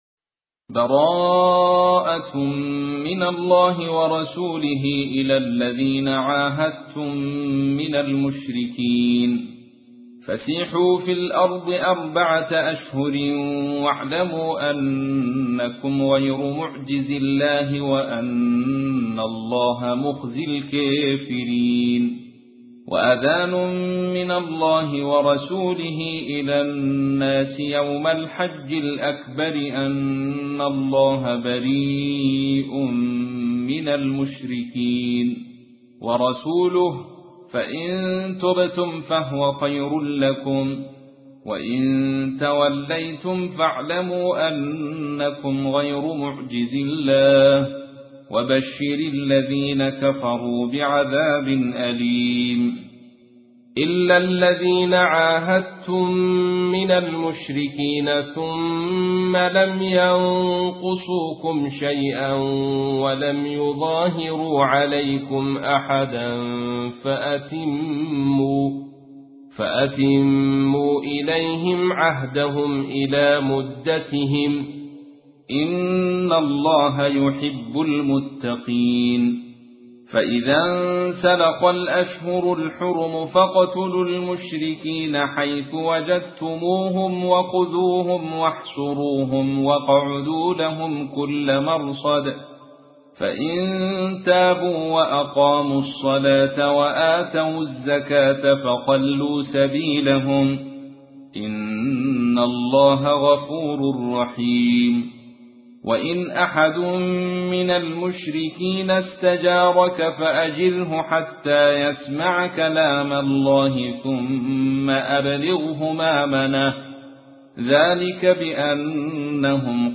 تحميل : 9. سورة التوبة / القارئ عبد الرشيد صوفي / القرآن الكريم / موقع يا حسين